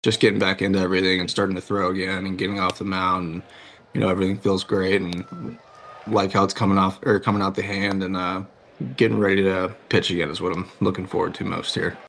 The Pirates’ Jared Jones continues to make progress toward a return to the mound after elbow surgery last May.  Jones threw off a mound for the first time last week and said yesterday on Sirius/MLB Network that he feels great.